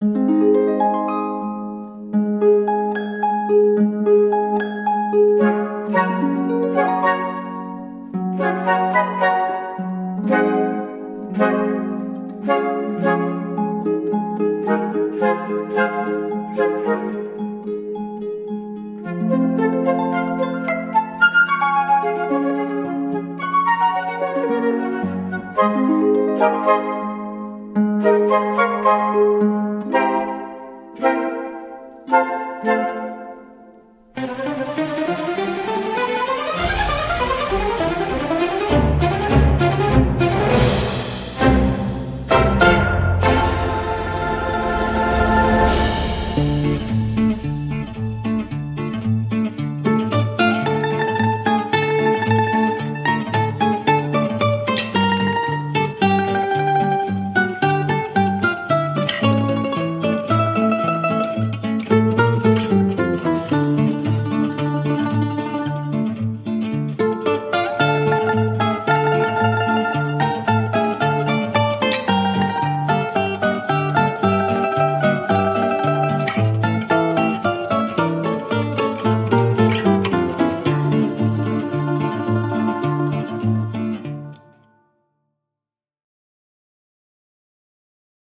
Un régal.